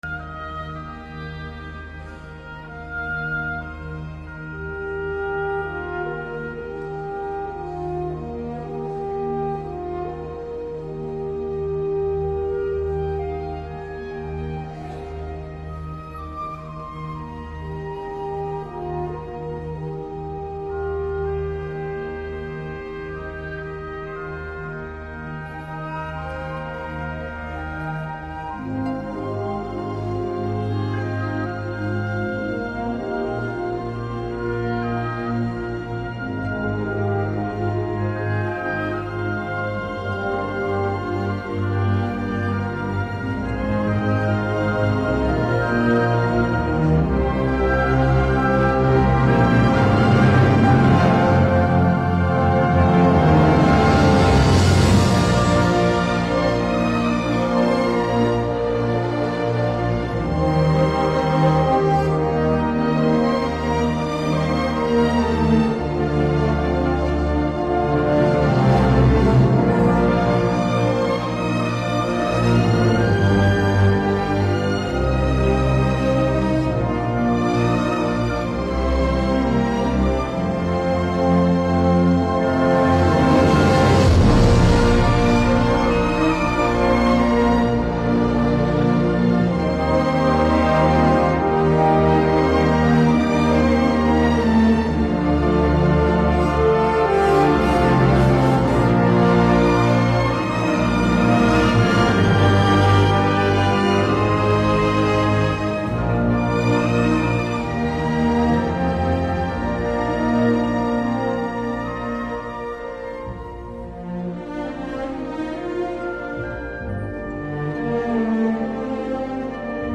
故事汇演：百年激荡青春潮
在“永远跟党走 奋进新时代”庆祝中国共产党成立100周年红色故事汇演中，该局税务青年干部以《中国共产党简史》为轴，结合本地红色印记和税收发展历程，用10个红色故事回顾新民主主义革命、抗日战争与解放战争、社会主义改造、改革开放及新时代中国特色社会主义等历史阶段，引导全市税务党员干部从红色故事中汲取精神力量，坚定永远跟党走的决心。